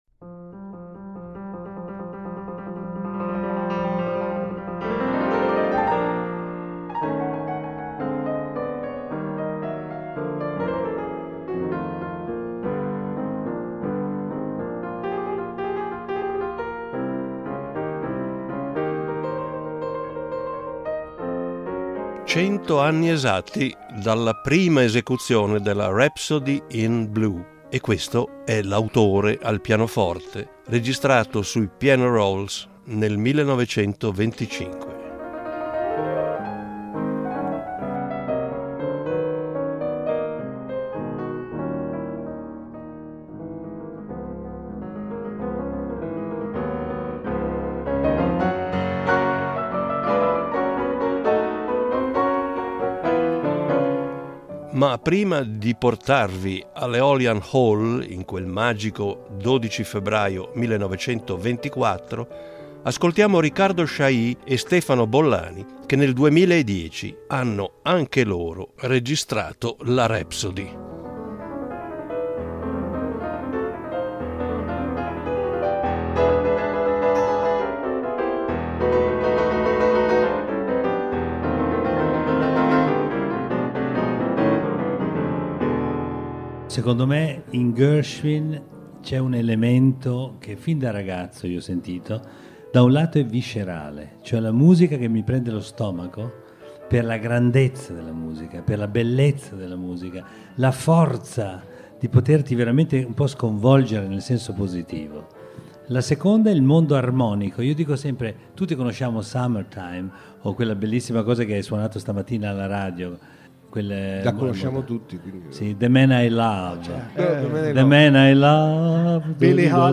Con le voci di Riccardo Chailly e Stefano Bollani
Con le voci di Riccardo Chailly e Stefano Bollani, e con l’ascolto di una puntata di uno sceneggiato sui fratelli Gershowitz (Ira e George) realizzato nel 2007 nei nostri studi di Lugano. Il pianoforte che ascolterete è suonato dallo stesso Gershwin su di un piano-roll del 1925, e trasferito con le moderne tecnologie su di un 9-foot Yamaha Disklavier grand piano.